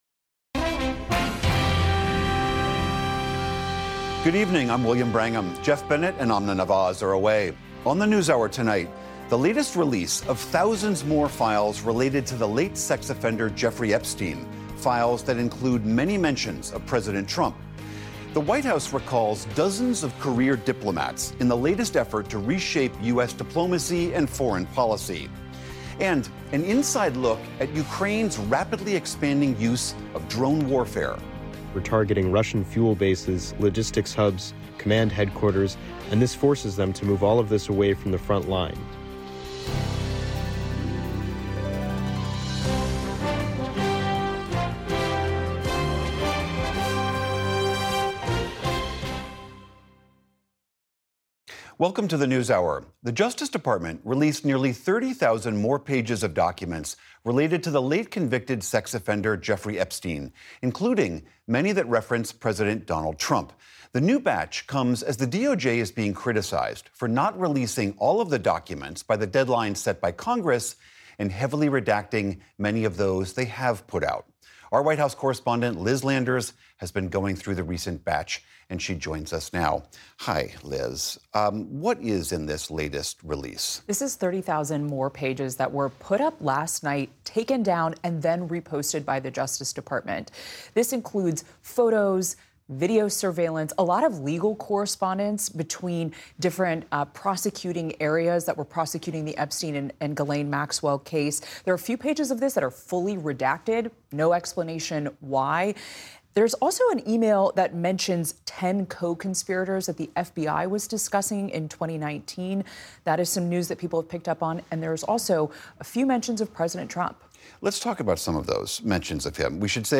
1 The Nightcap: Day 96 of Trump’s second presidency 43:05 Play Pause 1h ago 43:05 Play Pause Play later Play later Lists Like Liked 43:05 The Nightcap roundtable discusses Trump’s second term as the 100 day mark swiftly approaches.